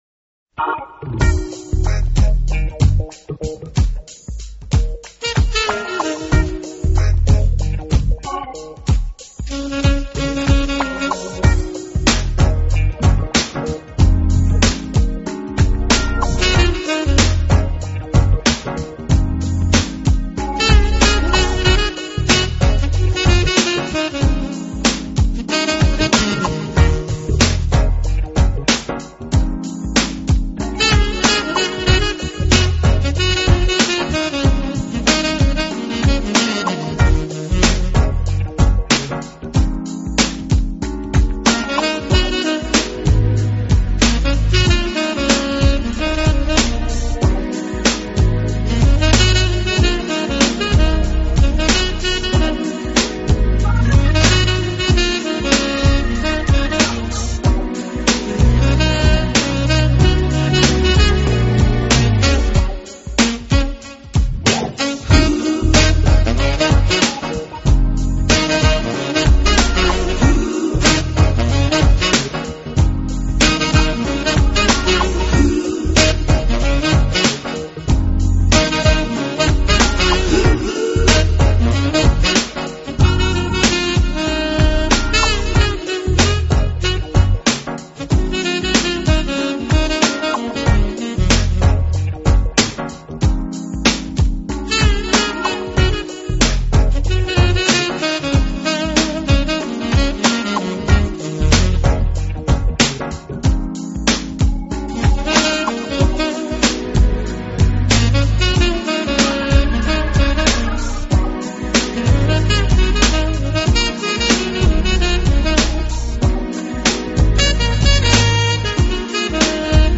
有着现代Jazz萨克斯风的演奏风格，